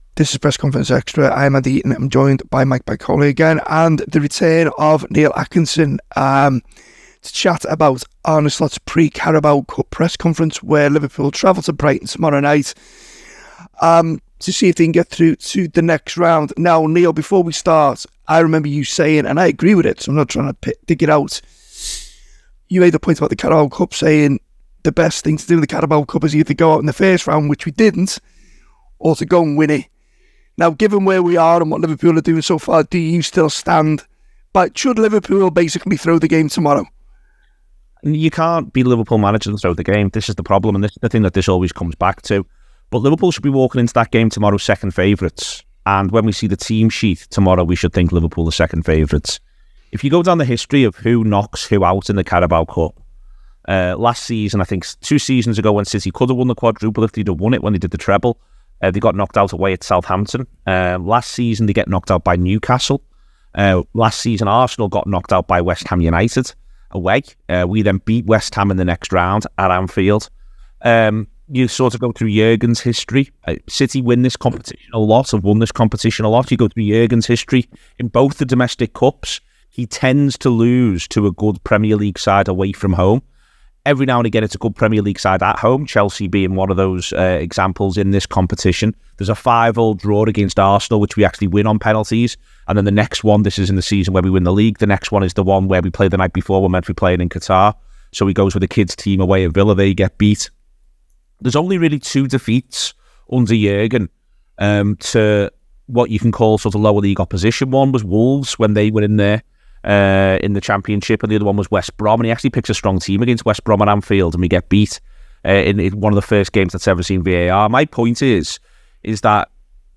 Below is a clip from the show – subscribe for more on the Brighton v Liverpool press conference…